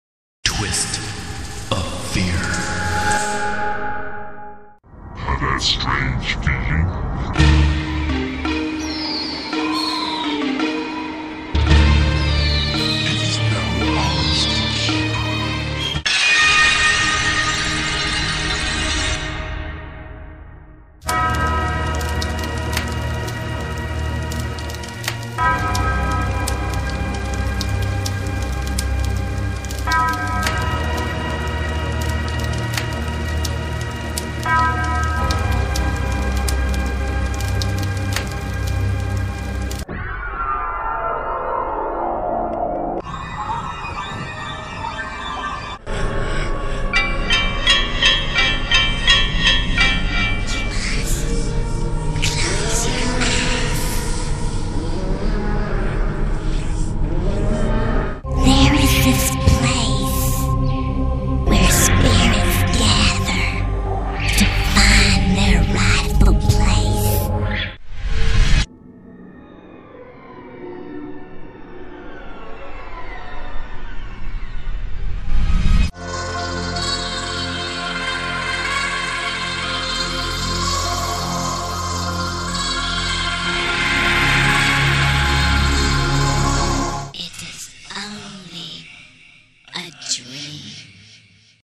Studio N
Hollywood Style Halloween Soundscapes